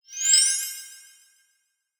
02_Heal_02.wav